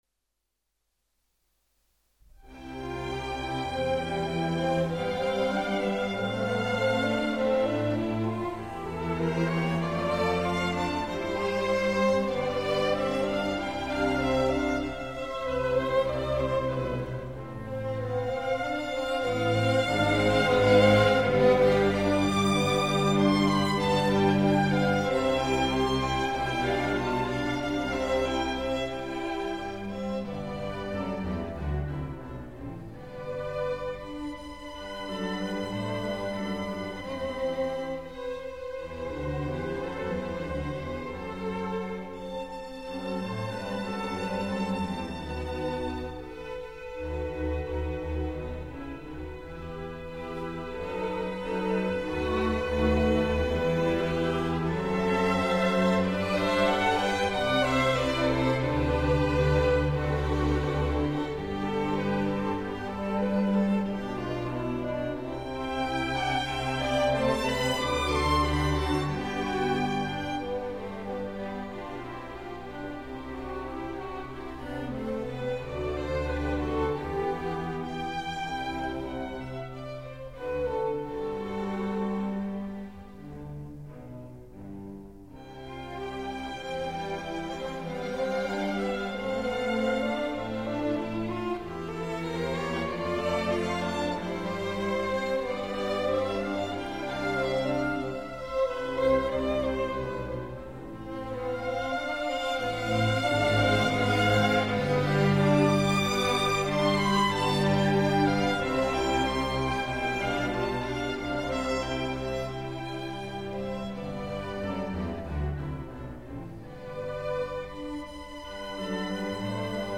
Andante